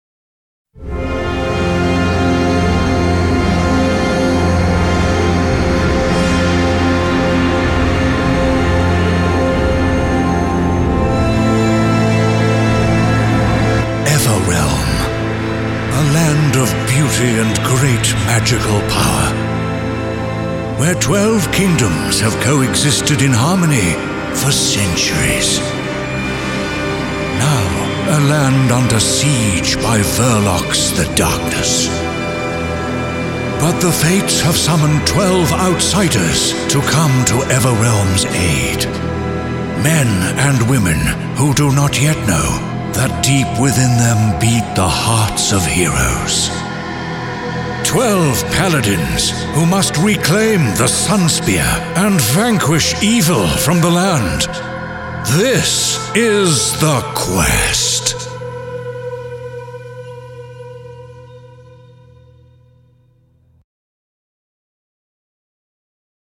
Examples of Gaming Voiceover – Performed By Peter Dickson